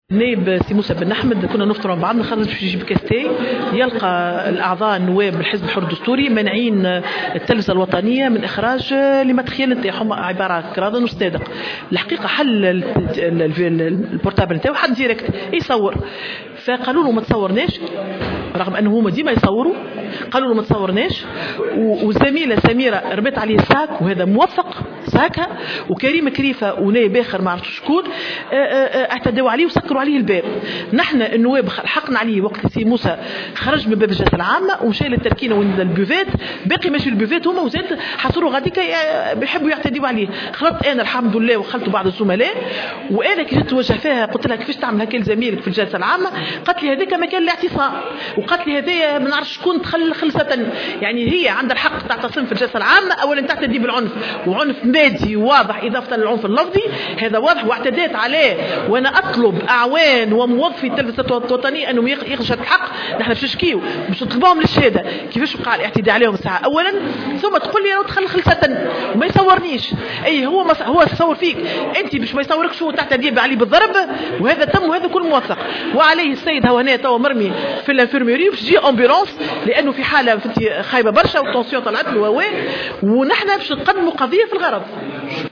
وأوضحت في تصريح لمراسل الجوهرة اف أم، أن النائب المذكور كان بصدد تصوير نواب كتلة الدستوري الحر أثناء تعطيلهم لعمل التلفزة الوطنية، وهو ما أثار غضبهم ودفعهم للاعتداء عليه بالعنف.